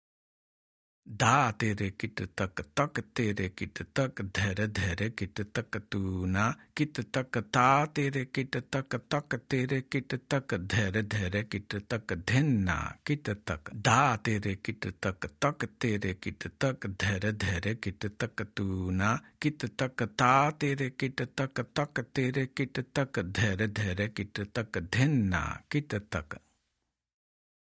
Spoken